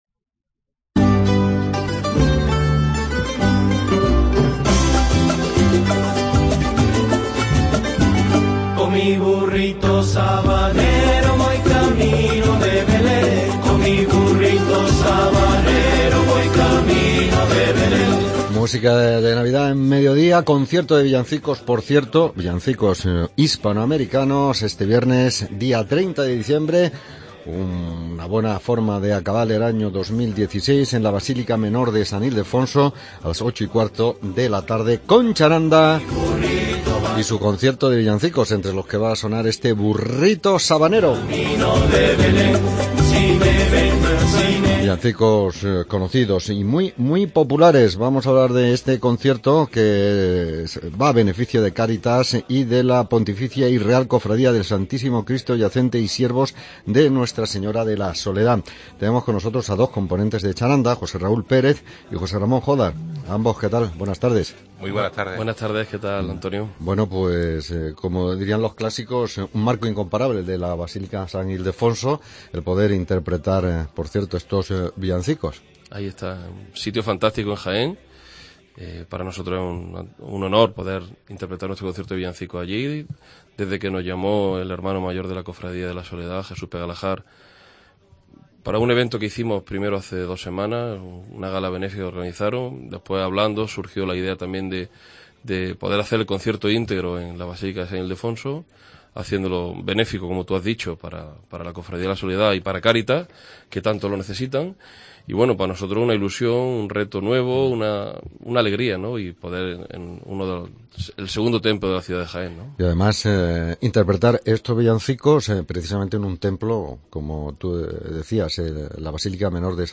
ENTREVISTA CHARANDA